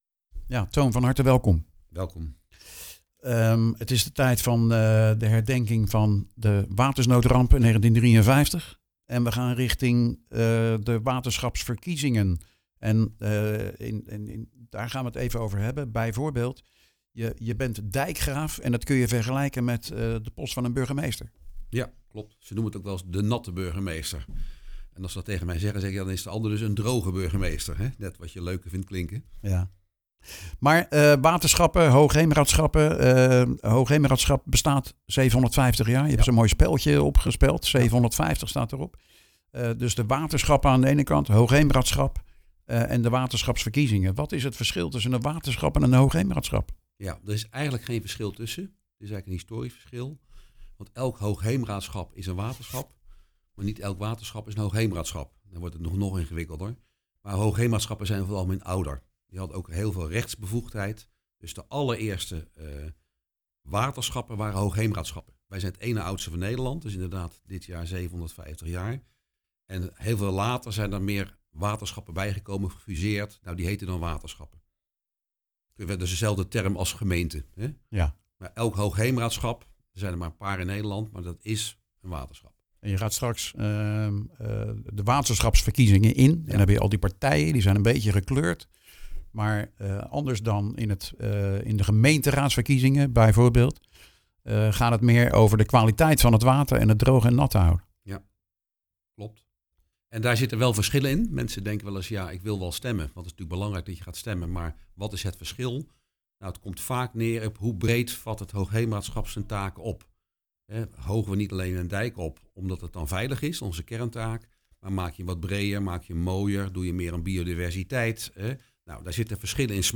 De herdenking van de watersnoodramp van 70 jaar geleden en de aankomende waterschapsverkiezingen. Meerdere redenen om met Toon van der Klugt, Dijkgraaf van het dit jaar 750 jaar oude Hoogheemraadschap van Schieland en de Krimpenerwaard te praten.